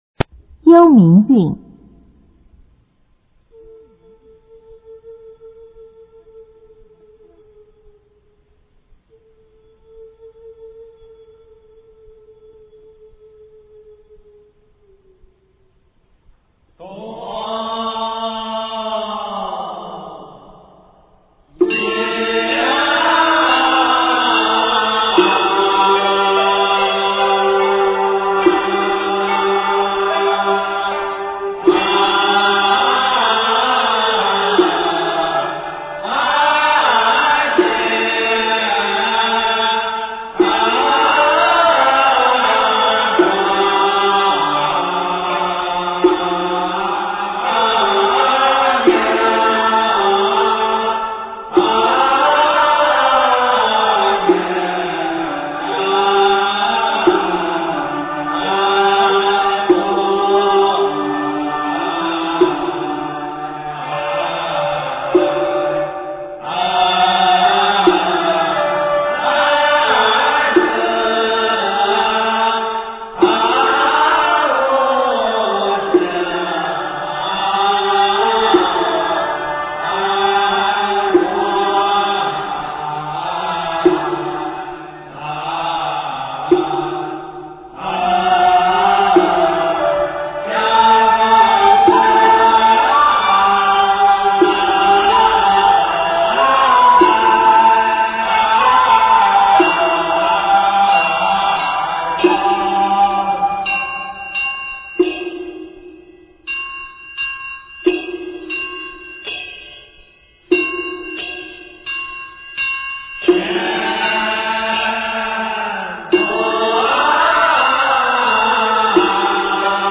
赏析：幽冥韵 对于救苦天尊的赞颂与祈求，咏述青华宫的景象，一唱三叹，旋律凄清委婉，动人心魄。